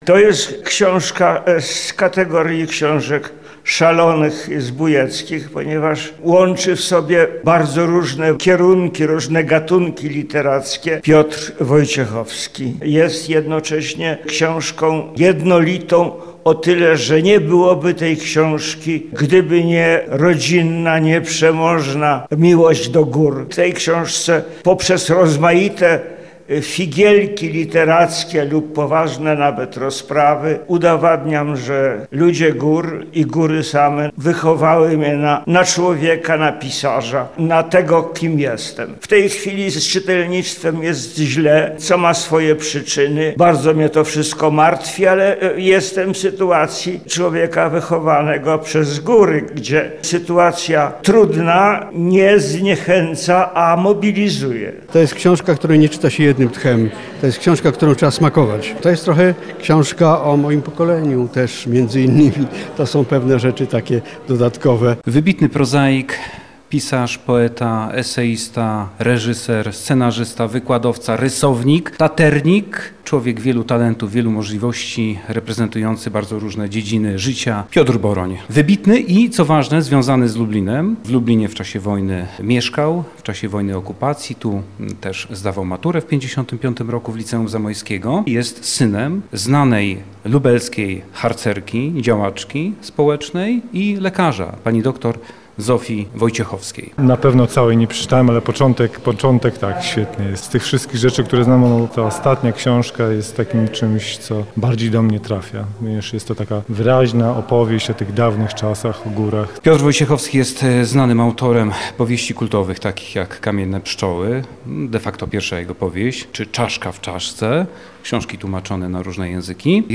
Autor podczas spotkania opowiedział o swojej twórczości, inspiracjach i górskich wątkach, które od lat powracają w jego pisaniu.